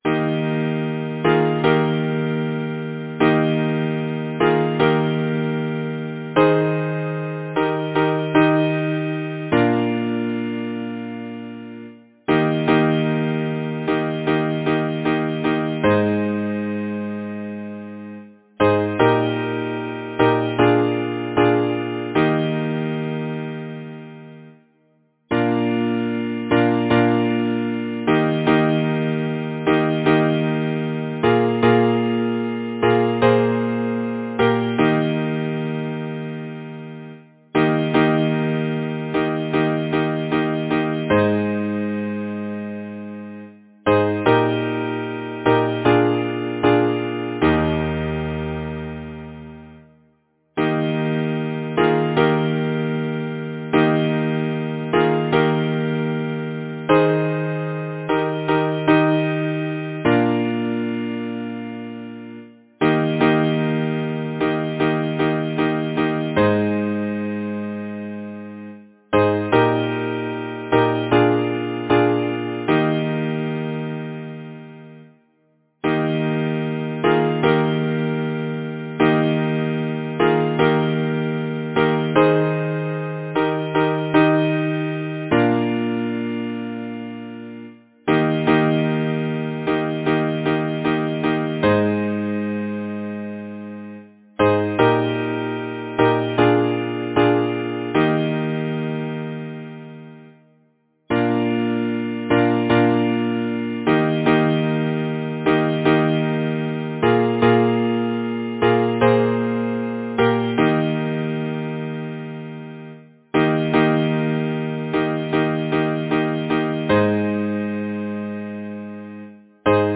Title: Home again Composer: Marshall Spring Pike Lyricist: Number of voices: 4vv Voicing: SATB Genre: Secular, Partsong
Language: English Instruments: A cappella